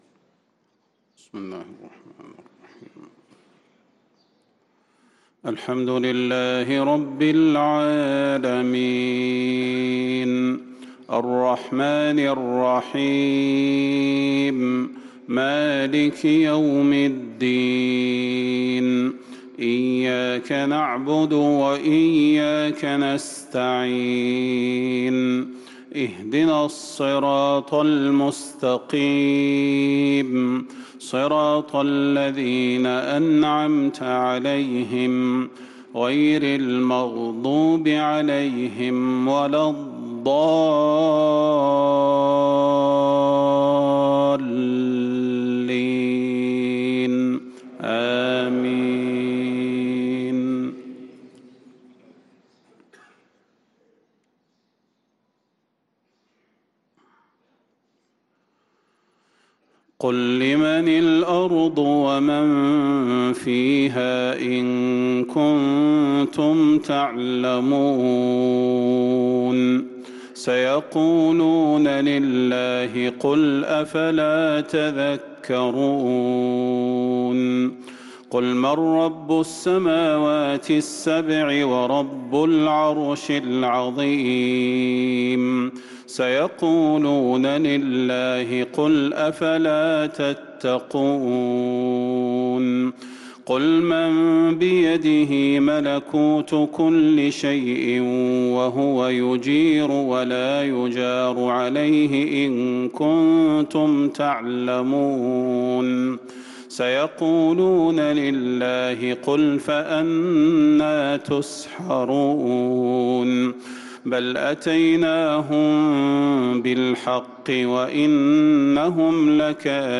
صلاة الفجر للقارئ صلاح البدير 22 رجب 1445 هـ
تِلَاوَات الْحَرَمَيْن .